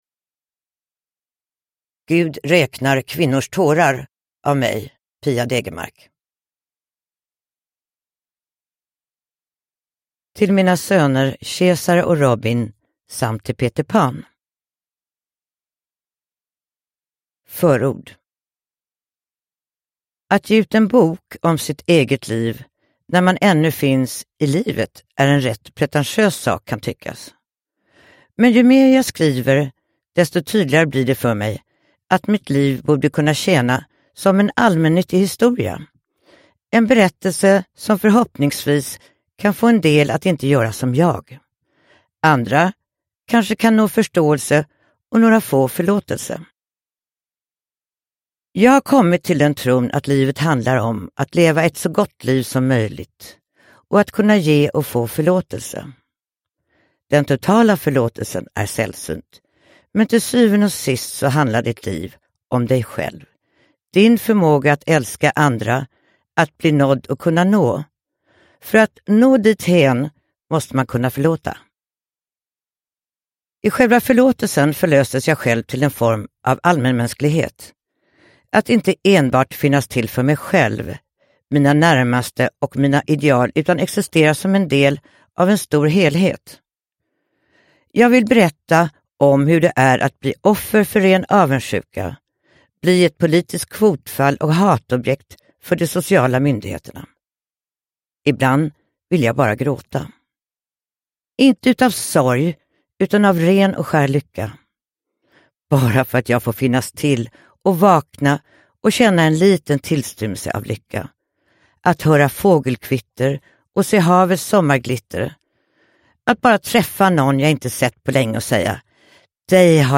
Gud räknar kvinnors tårar : memoarer – Ljudbok – Laddas ner
Uppläsare: Pia Degermark